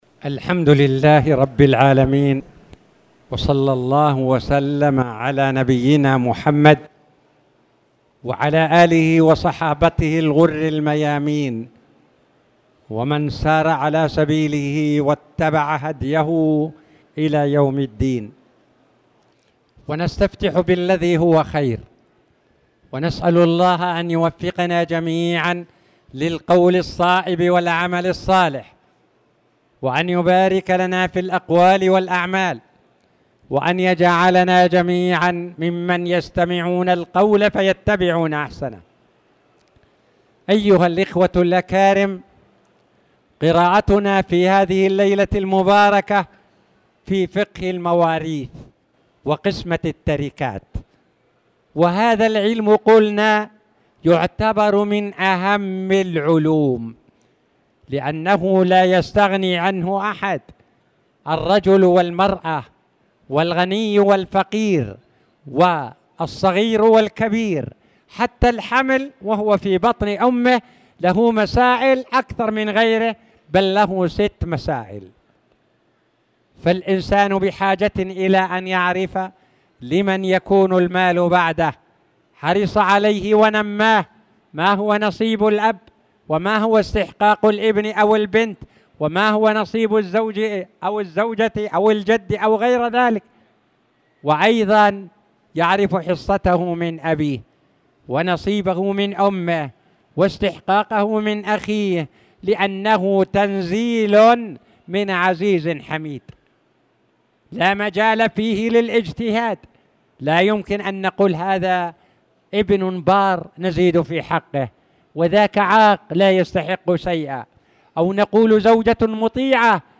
تاريخ النشر ٢٢ شوال ١٤٣٧ هـ المكان: المسجد الحرام الشيخ